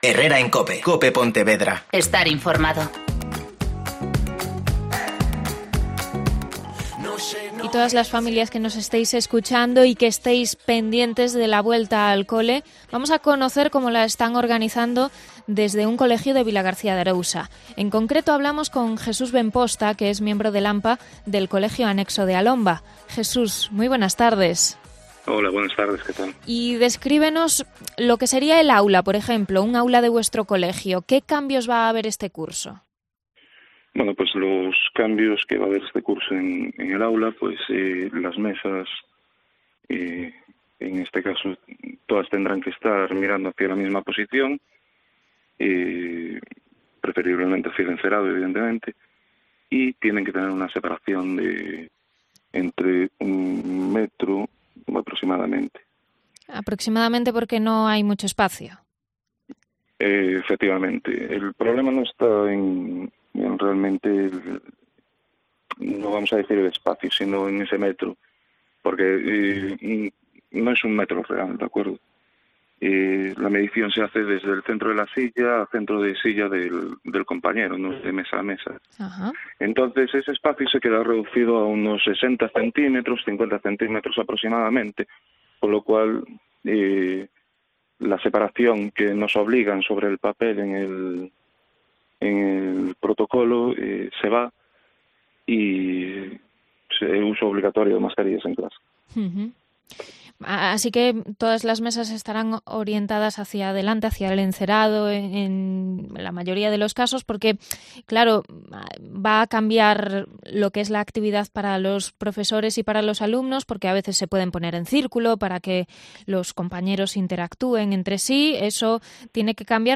Entrevistas a responsables del ANPA del Anexo de A Lomba y la consellería de Educación